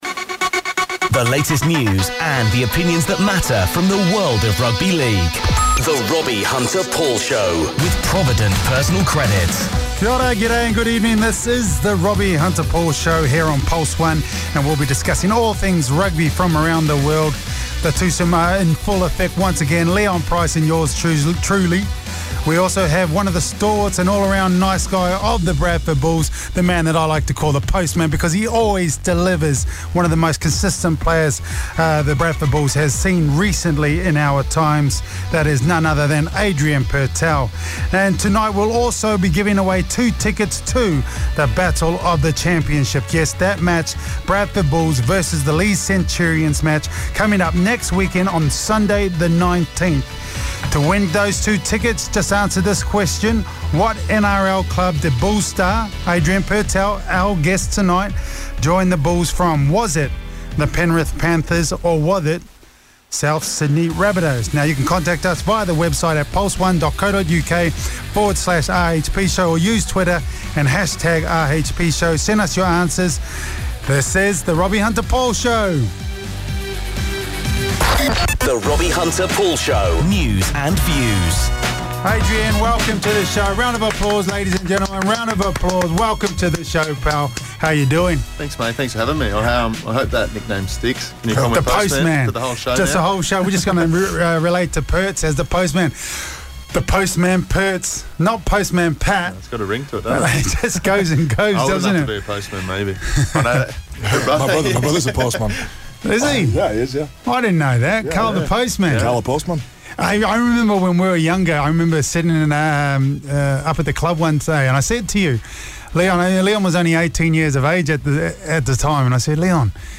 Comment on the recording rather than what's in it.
live in the studio.